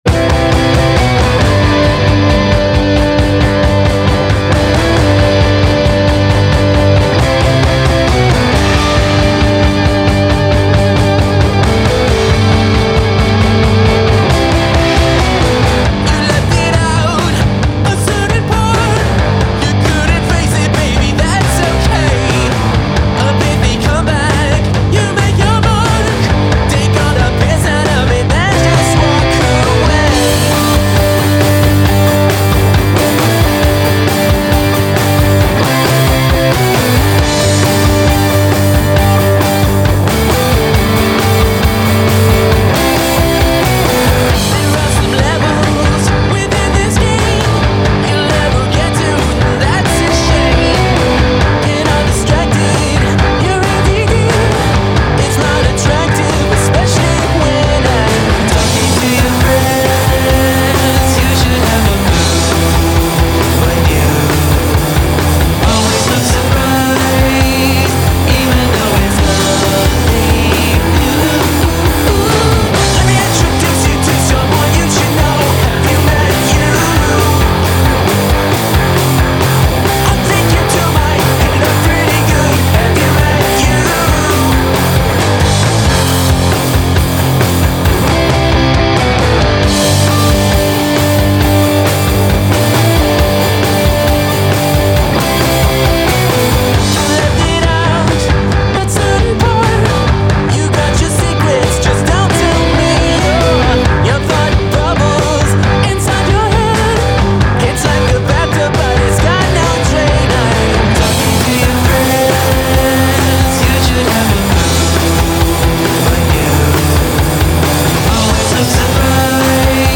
an unexpected knack for pop/rock